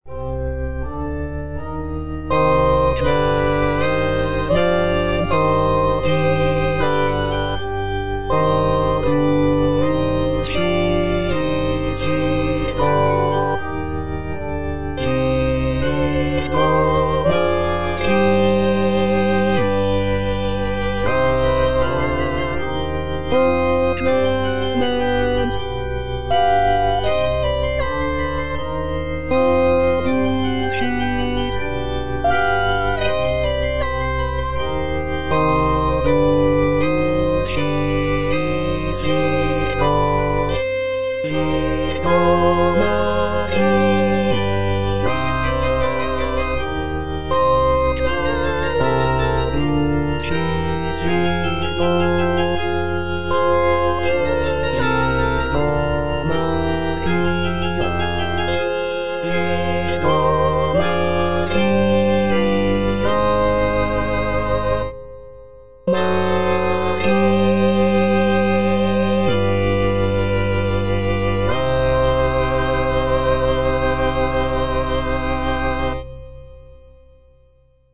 G.B. PERGOLESI - SALVE REGINA IN C MINOR O clemens, o pia (All voices) Ads stop: auto-stop
"Salve Regina in C minor" is a sacred choral work composed by Giovanni Battista Pergolesi in the early 18th century.
The piece is scored for soprano soloist, string orchestra, and continuo, and showcases Pergolesi's skill in writing for voice and orchestra.